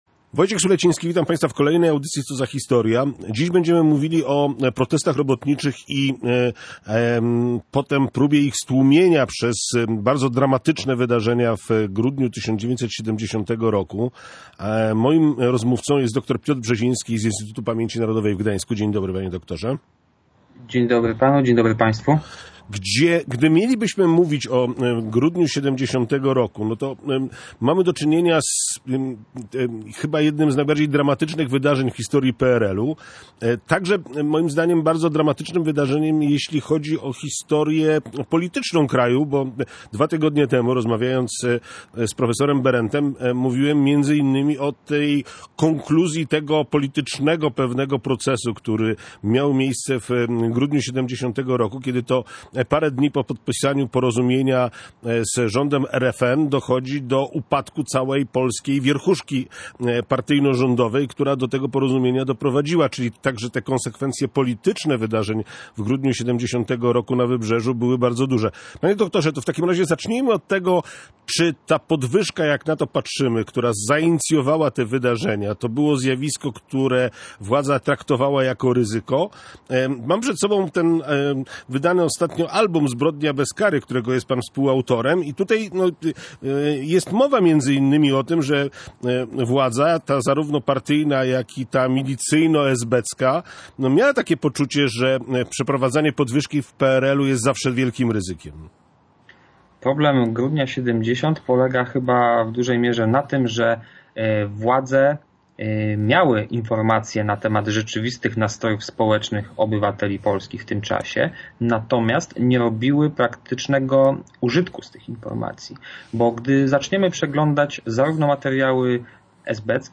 Meldujący funkcjonariusze mieli tego świadomość – mówił gość Radia Gdańsk.